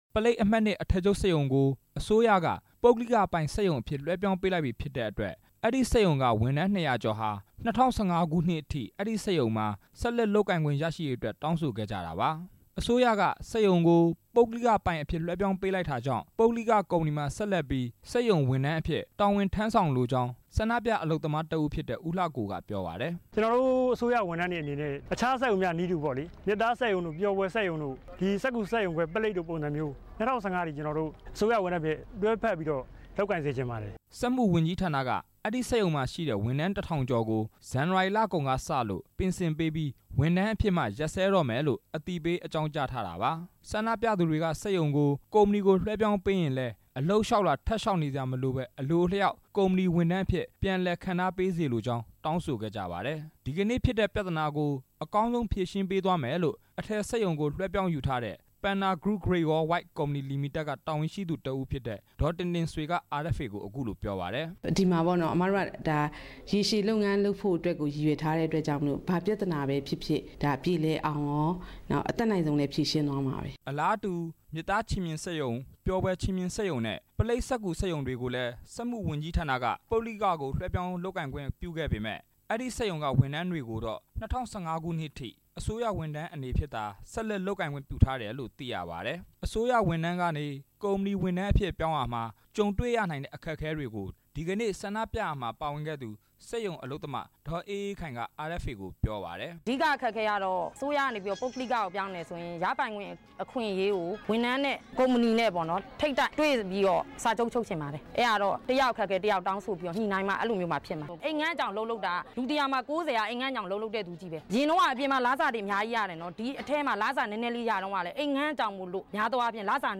ဆန္ဒပြပွဲအကြောင်း သတင်းပေးပို့ချက်